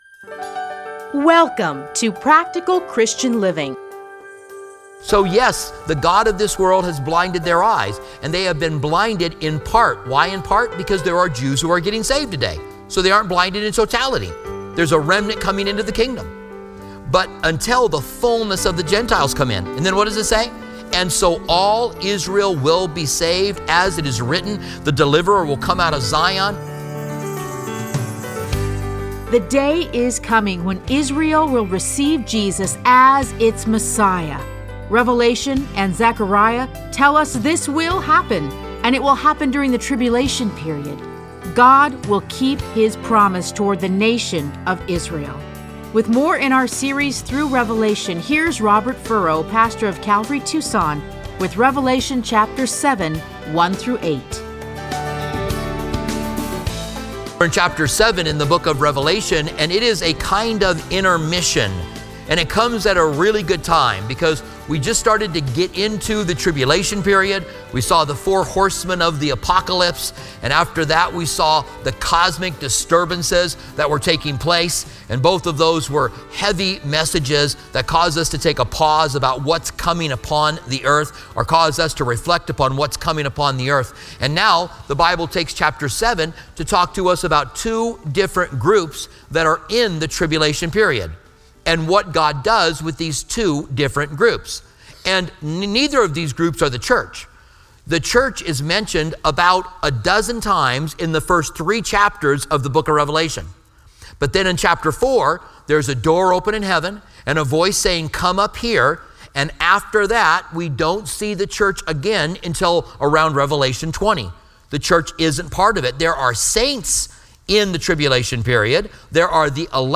Listen to a teaching from Revelation 7:1-8.